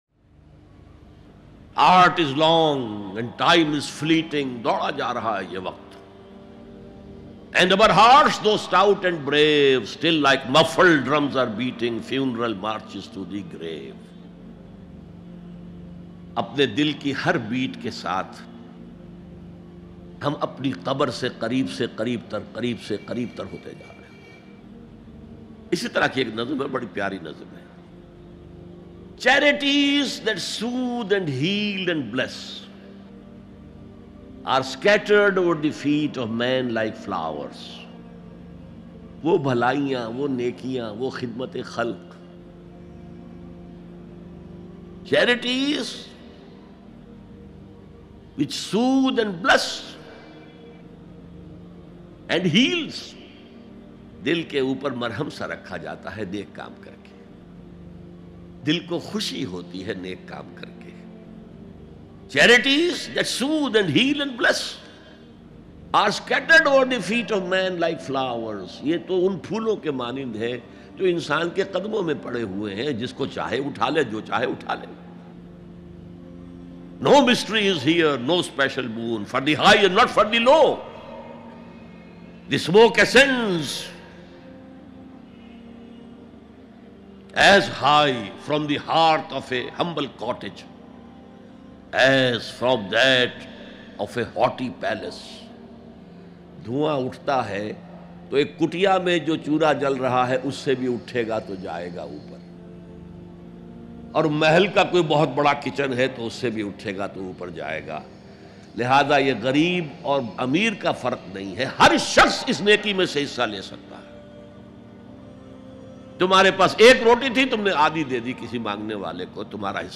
ALLAH Kon Si Neki Qabool Karta Hai Bayan MP3 Download Dr Israr Ahmad